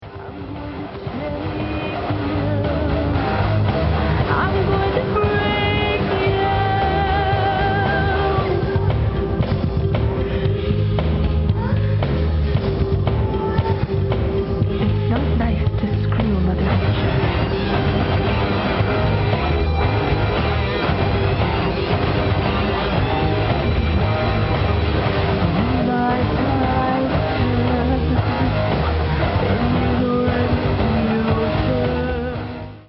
percussion & rhythm programming
keyboards,vocal(megaphone scripture quotations)
guitar
leadguitar
bass